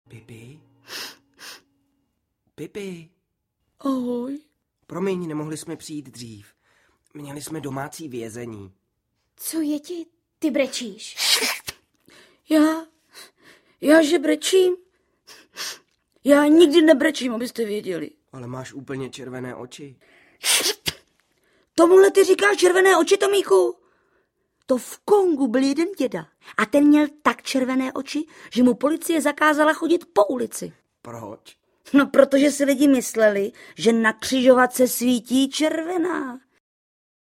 Pipi Dlouhá punčocha audiokniha
Ukázka z knihy